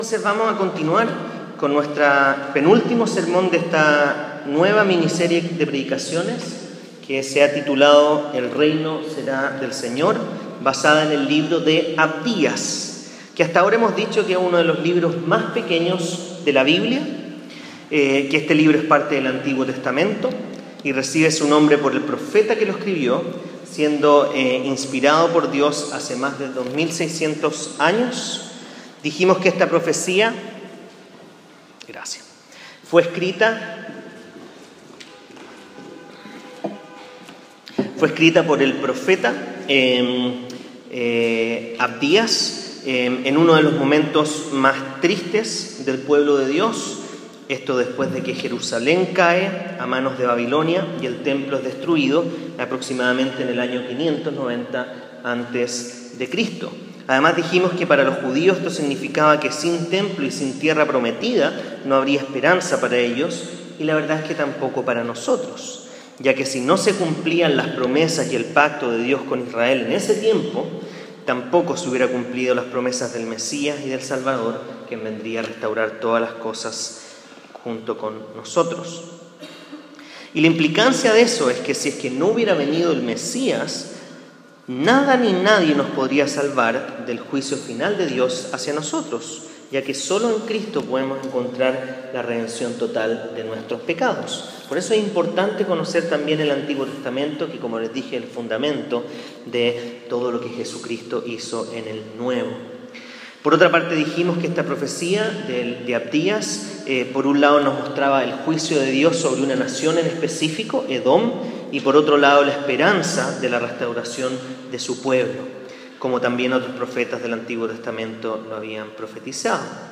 Fecha: 24/06/16 Sermón: Consecuencias de la Maldad. Lectura: Abdías 10-14.